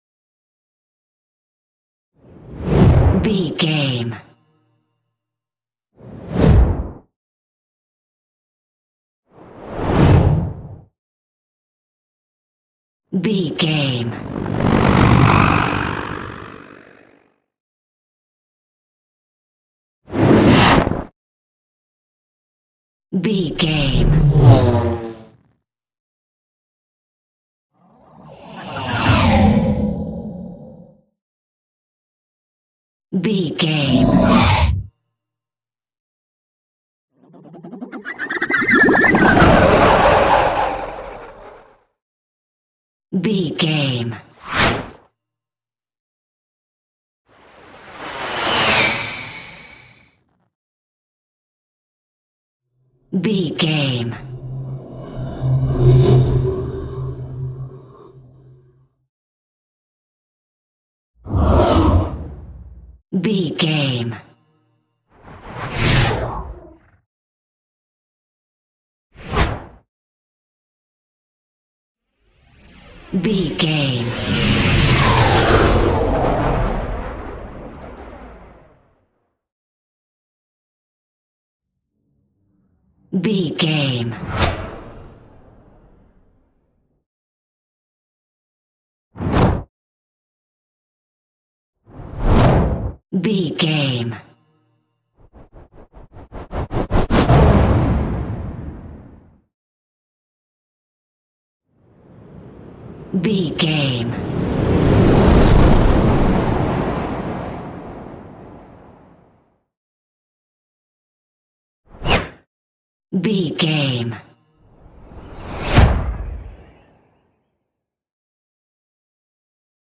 Trailer Whoosh Collection 2
Sound Effects
Fast paced
In-crescendo
Atonal
high tech
intense
whoosh